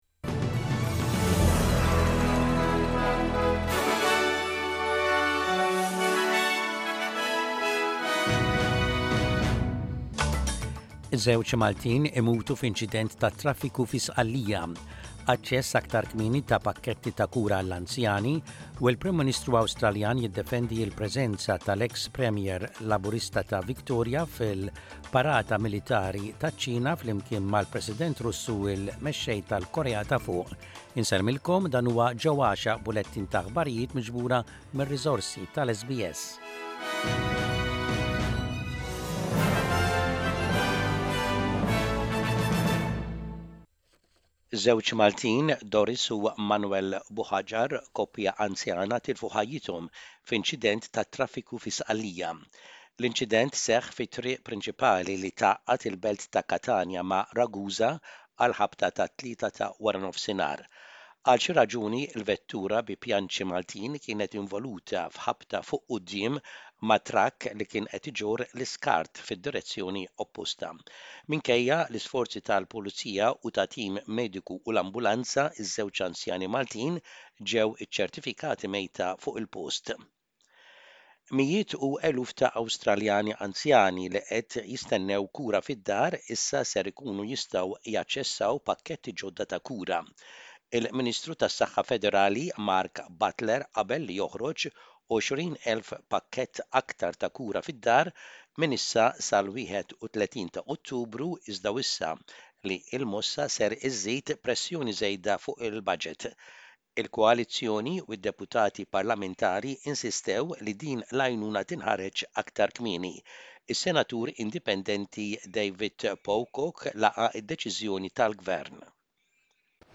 Aħbarijiet bil-Malti